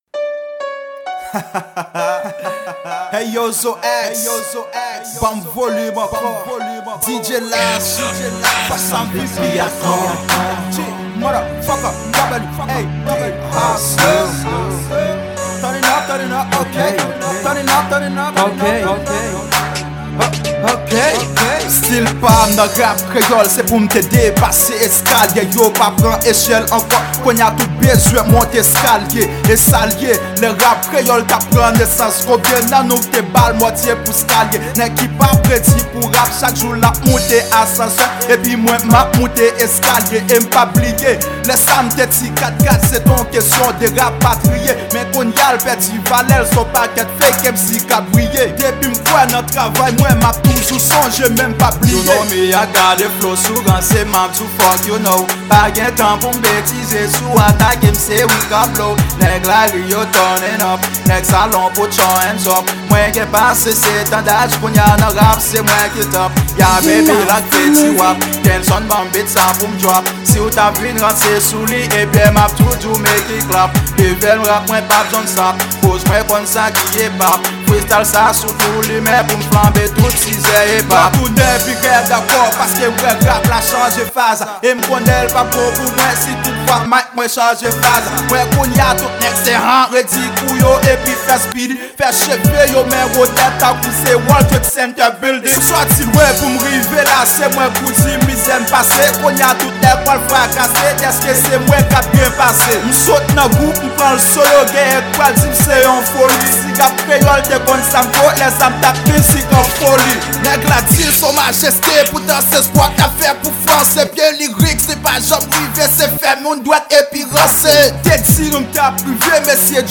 Genre: Rap-Freestyle.